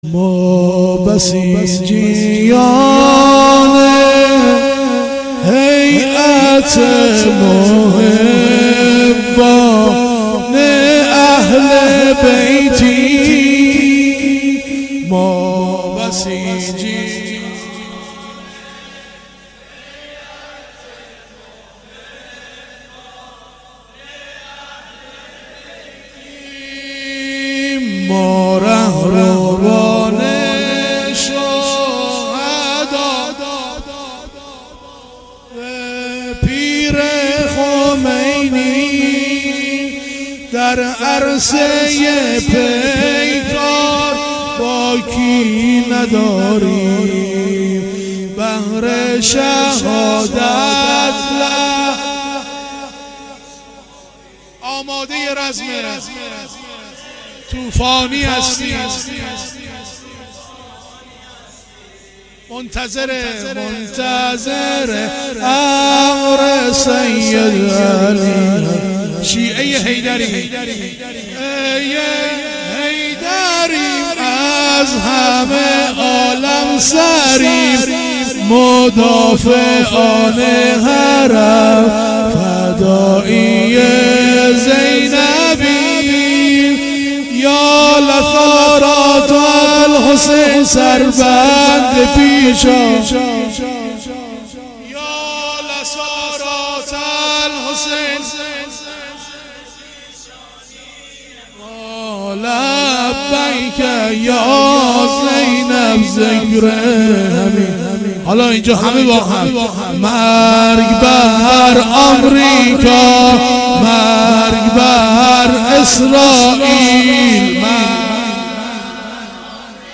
شعار هیأت شب دوم محرم 96